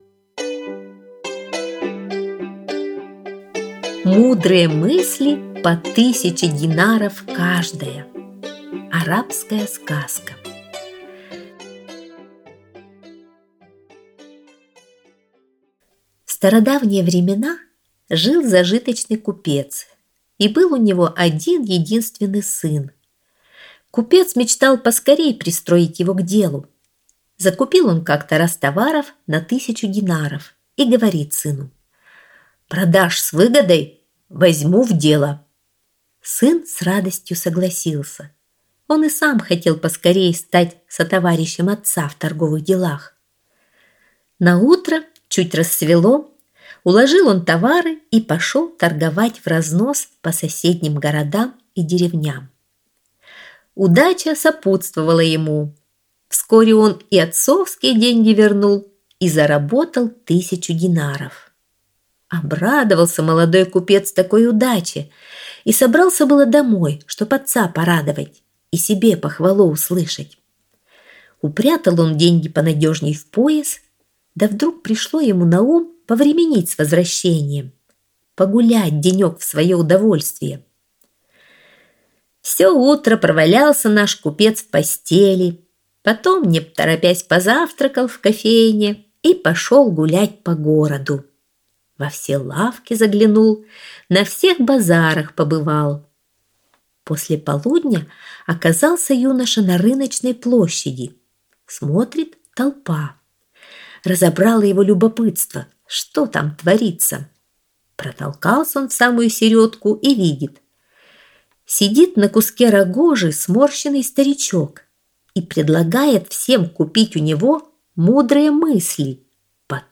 Мудрые мысли по тысяче динаров каждая - арабская аудиосказка